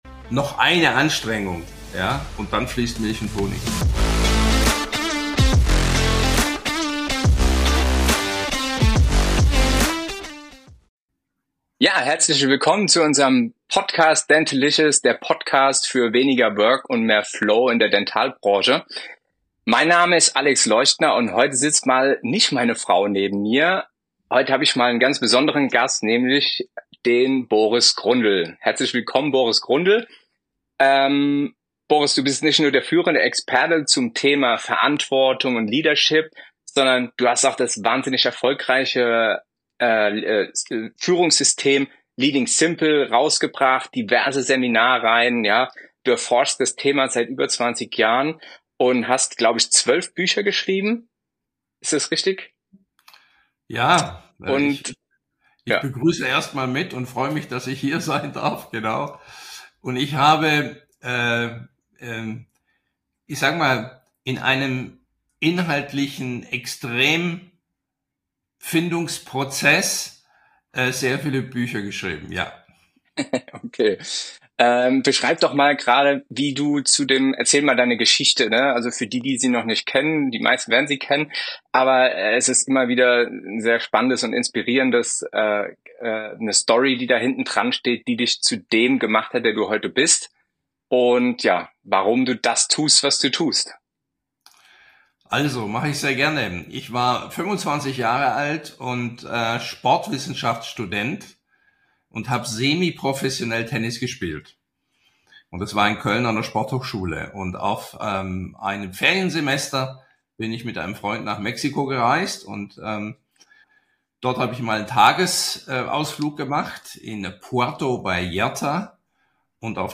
ein tiefes Gespräch über Veränderungskompetenz und wie Zahnarztpraxen und -labore erfolgreich mit der ständigen Veränderung in der Branche umgehen können